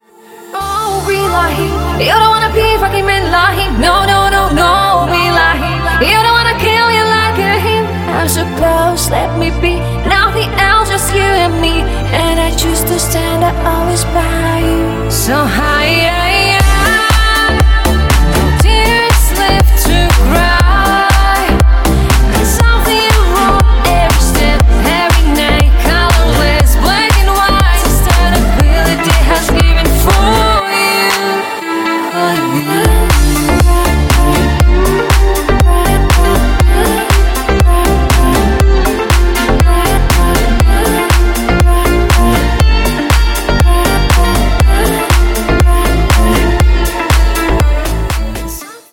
женский вокал
deep house
веселые
Electronic
EDM
Отличный танцевальный дип хаус